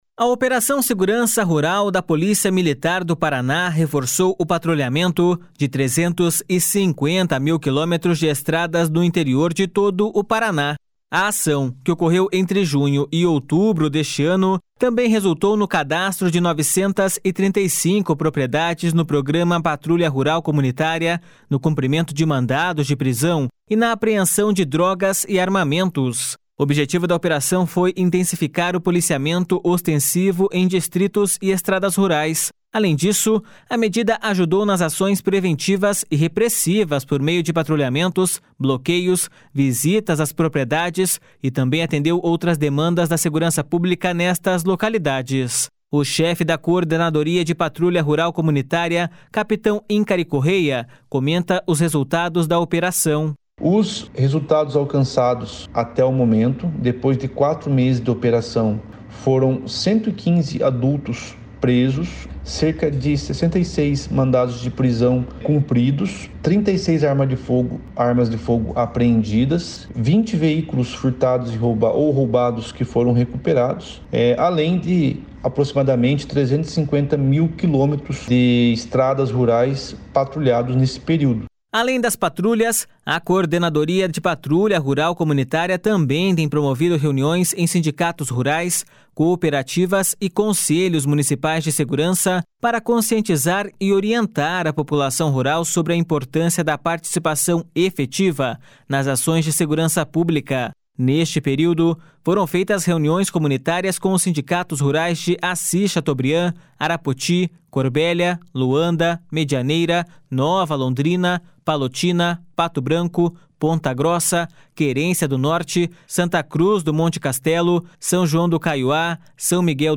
OPERACAO RURAL DA PM_0.mp3